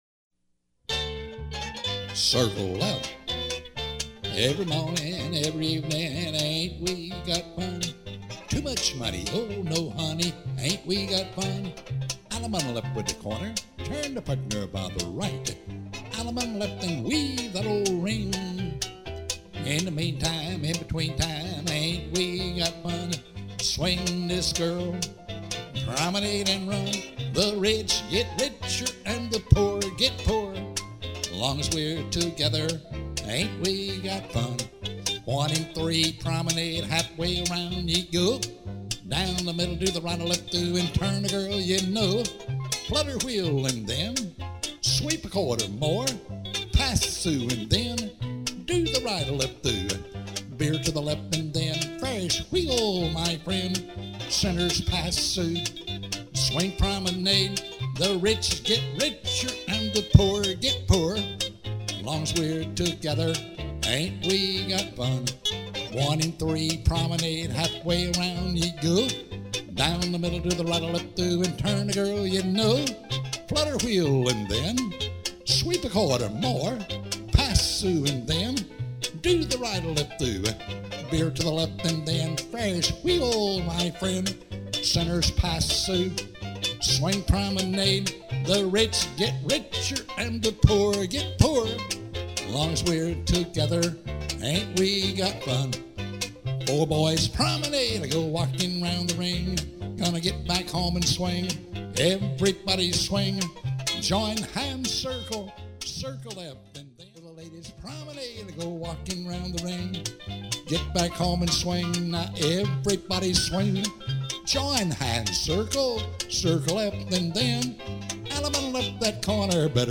Record Type: Singing Call